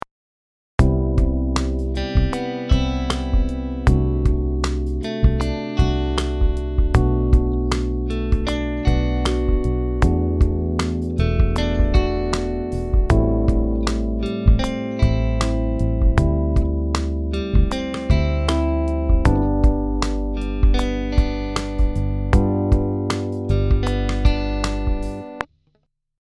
ギターのアルペジオにかけてみたらどうなるの？
ノンエフェクトで聴くとちょっとこっ恥ずかしい感じになりますね。